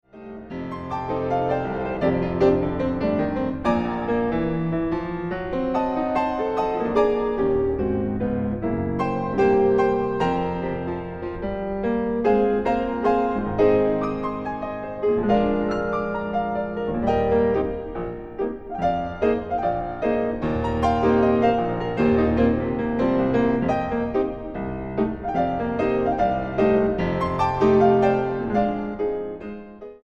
pianista